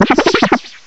cry_not_spewpa.aif